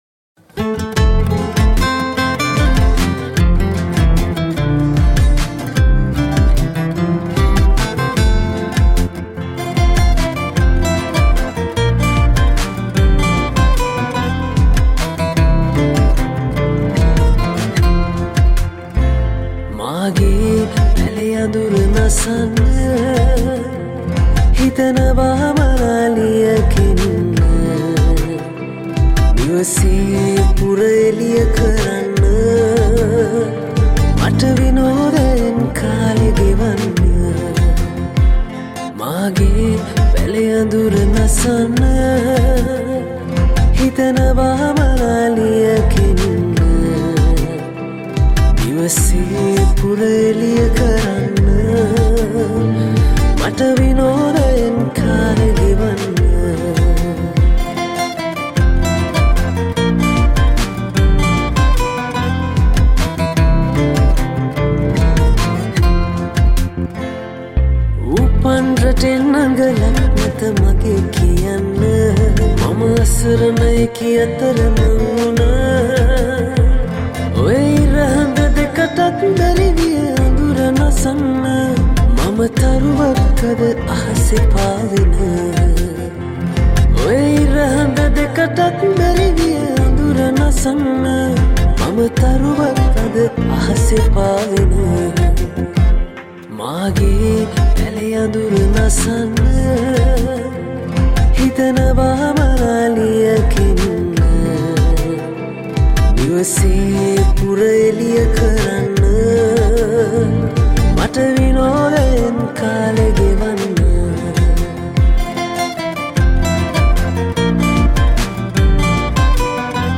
Cover music